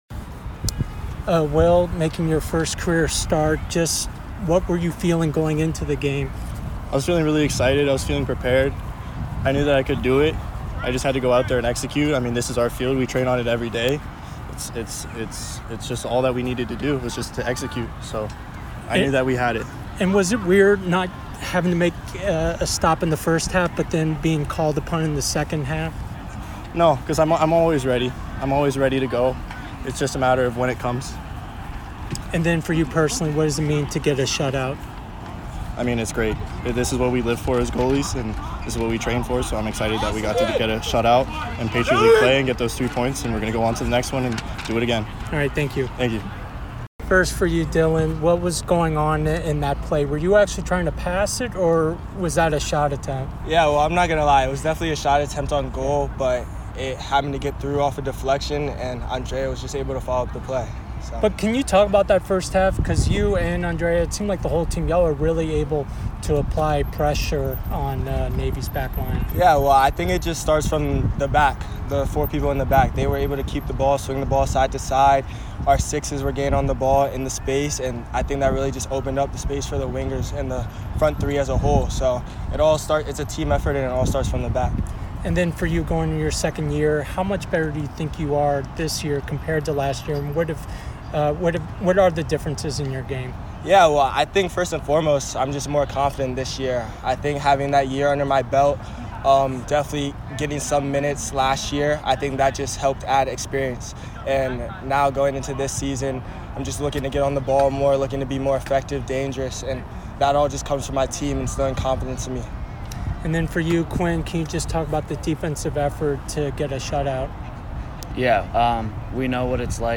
Men's Soccer / Navy Postgame Interview